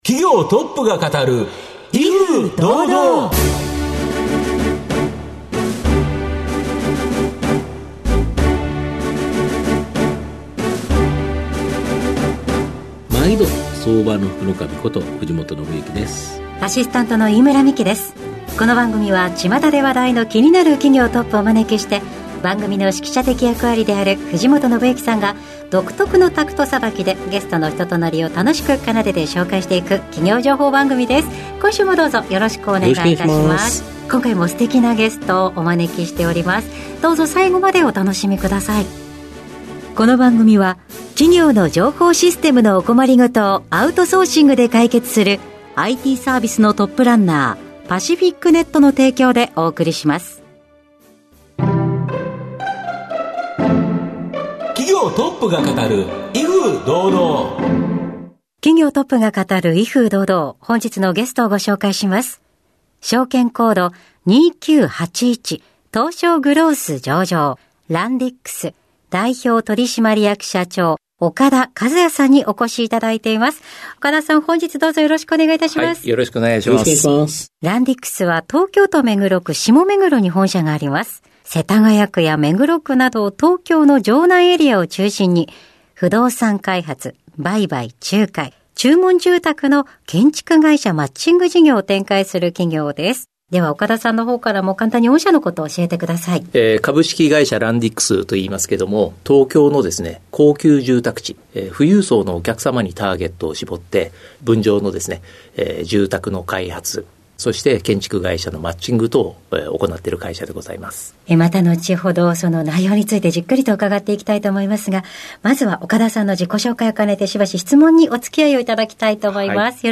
経営トップをゲストにお招きして事業展望や経営哲学などをうかがいつつ、トップの人となりにも迫るインタビュー番組です。番組の最後に毎回ゲストがピックアップする「四字熟語」にも注目！